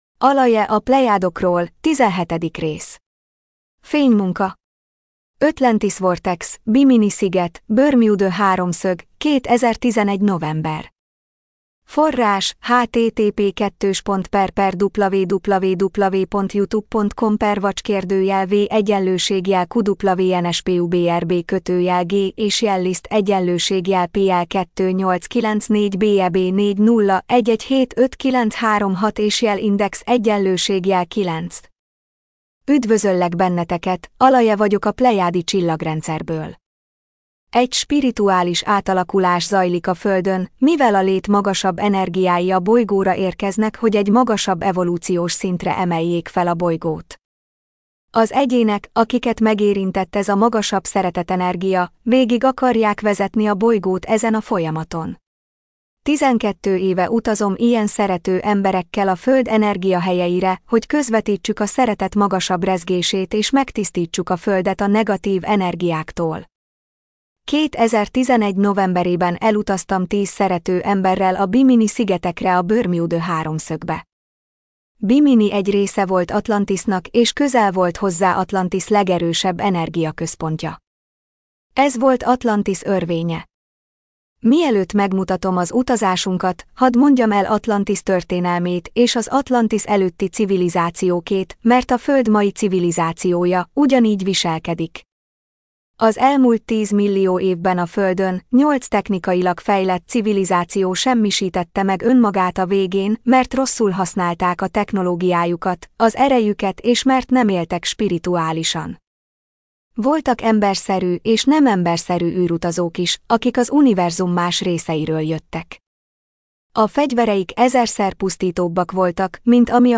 MP3 gépi felolvasás Magyar Alaje Honlap - Videók - 17. rész Alaje a Plejádokról - 17. rész Fénymunka Atlantis vortex, Bimini sziget, Bermuda háromszög - 2011 november.